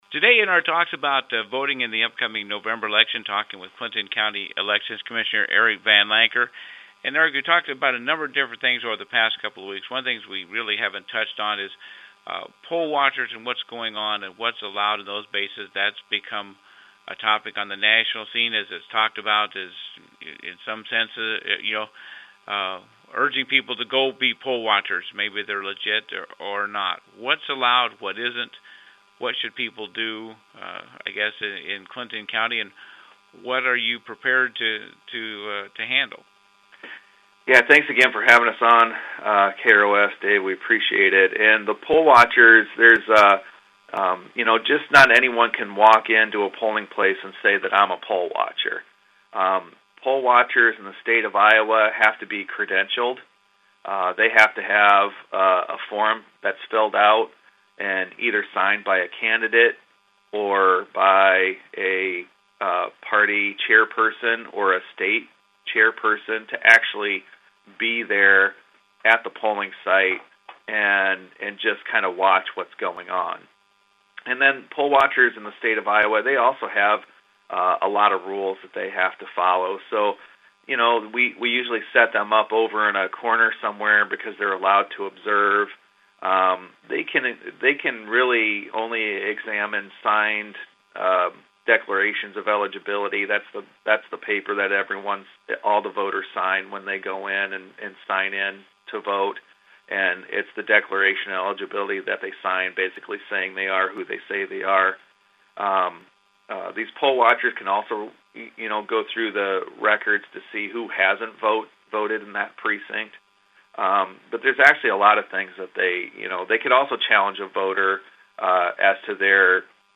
In the KROS weekly conversation with Clinton County Elections Commissioner Eric Van Lancker about voting in the election we discuss poll watchers and what is and isn’t allowed and in-person absentee voting and your absentee ballot.